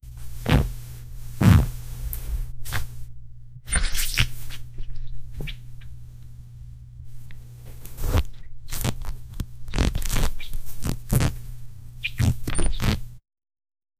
100 Fart sounds of various types in general 0:47 Created Oct 30, 2024 7:07 PM Hard and long squelch sound two wet objects making squelching sounds. Deep and soft sound as if human skin 0:14 Created Nov 18, 2024 6:29 PM
hard-and-long-squelch-sou-2orspgkv.wav